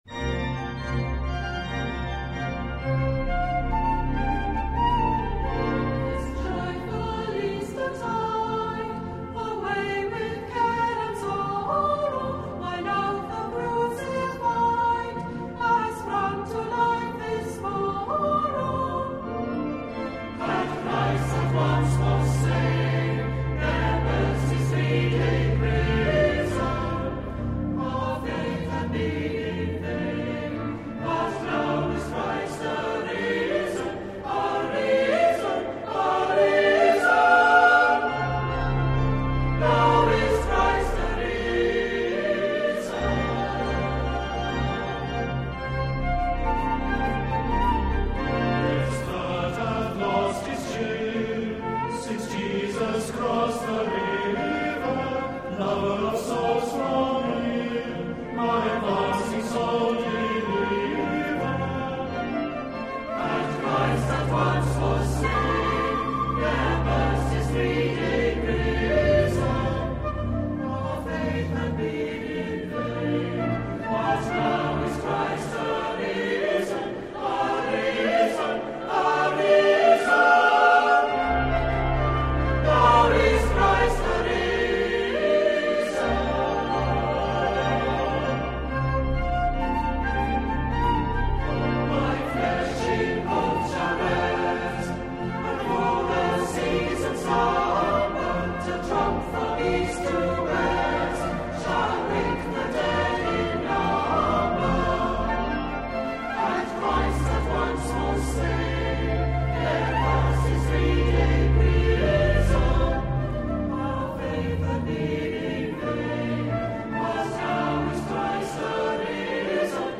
Accompaniment:      Keyboard, Flute;Violin
Music Category:      Christian
The flute or violin part is optional.